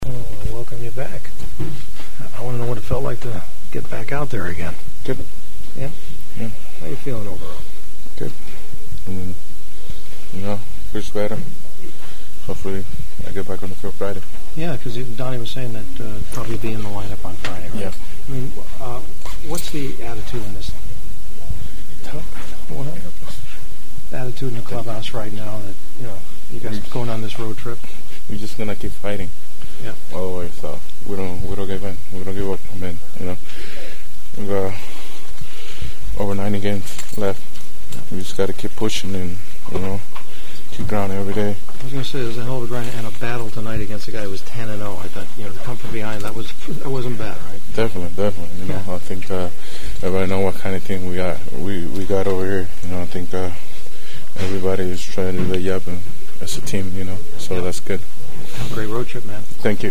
Dodger Hanley Ramirez returned to pinch hit and said he should be ready to start again on Friday in Pittsburgh: